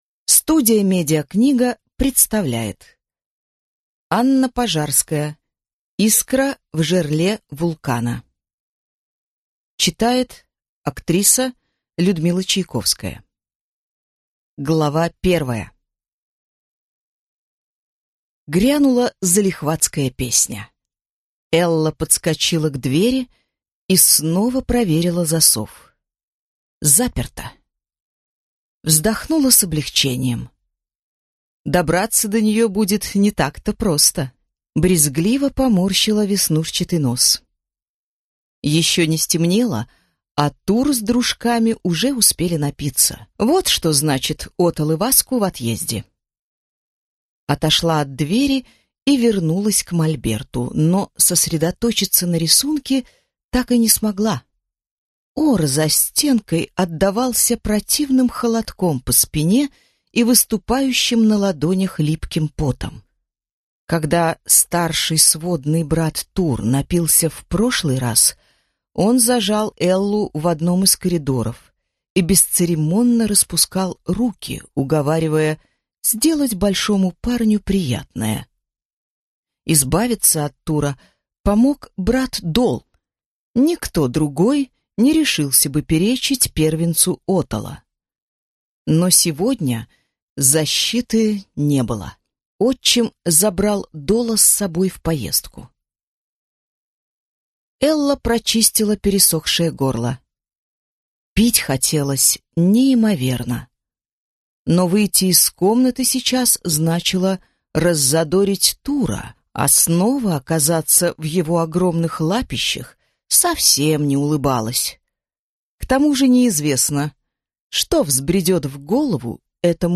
Аудиокнига Искра в жерле вулкана | Библиотека аудиокниг